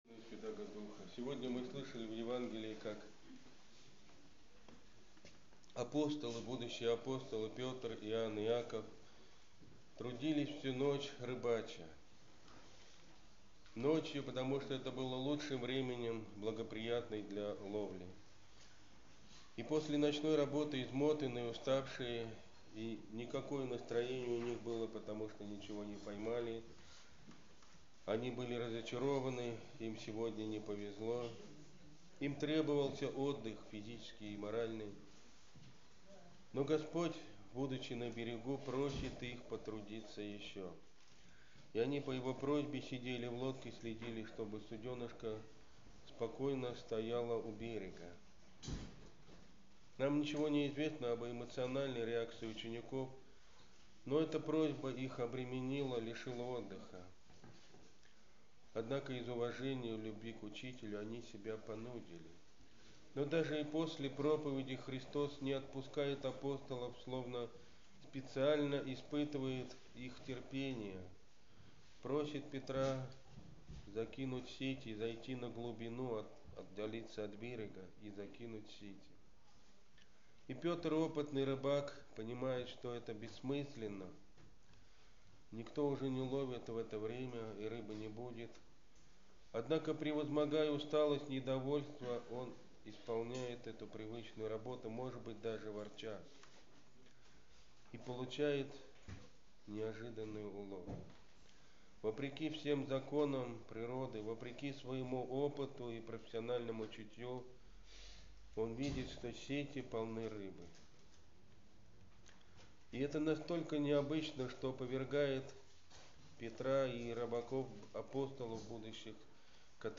Аудиопроповеди